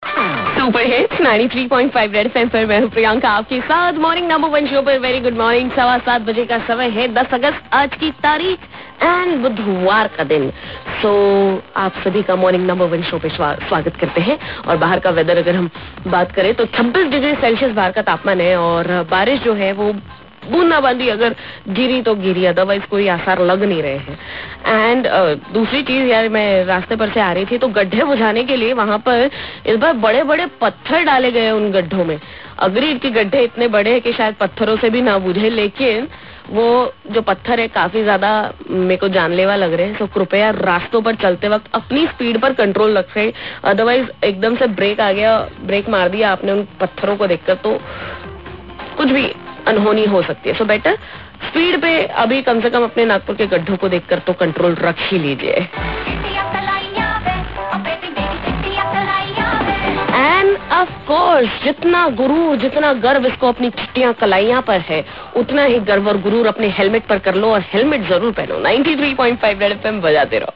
weather update